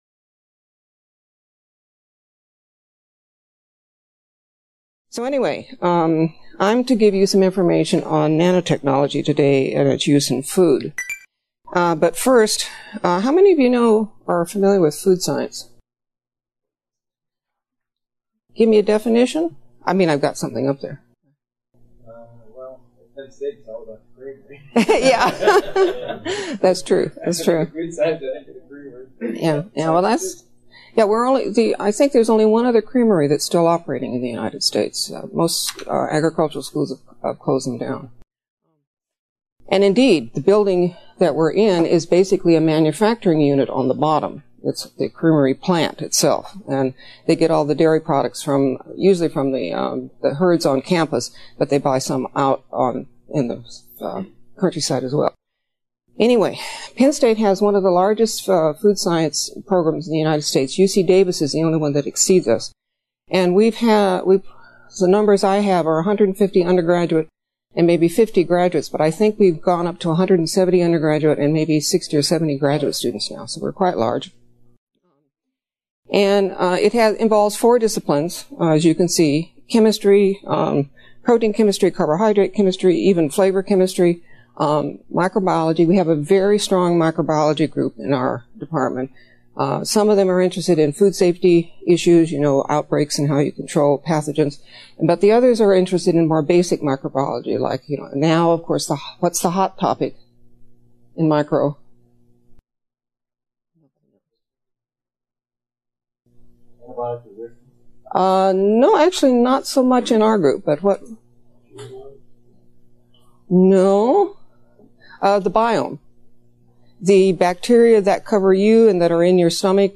This webinar, published by the Nanotechnology Applications and Career Knowledge Support (NACK) Center at Pennsylvania State University, provides an overview of the use of nanoscale science and technology for food processing and manufacturing.